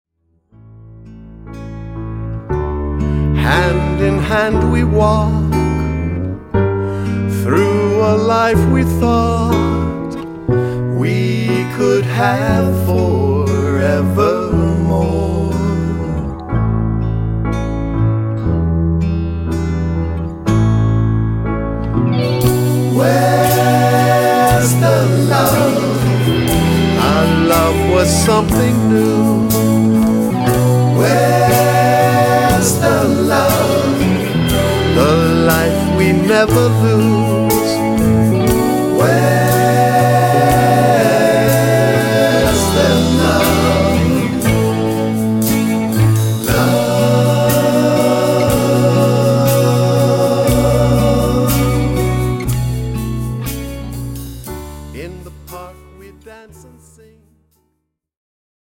The meaningful, lyrical reprise